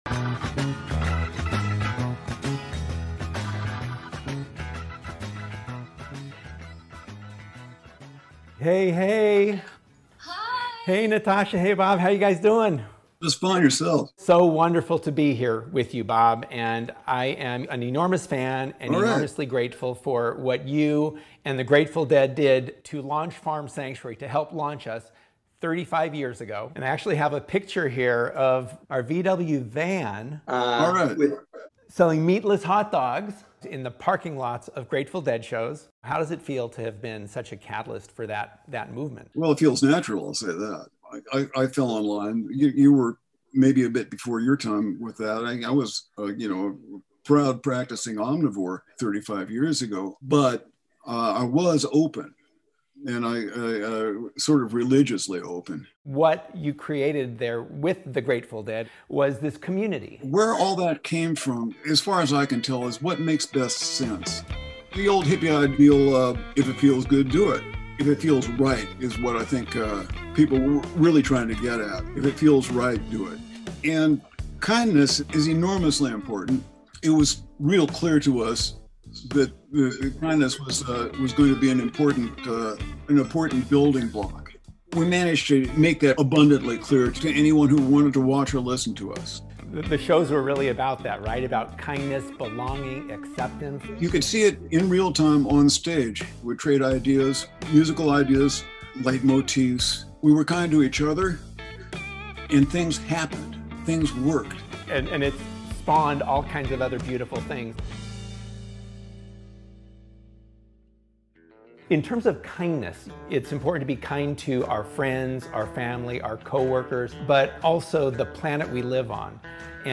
Rooted In Kindness: A Conversation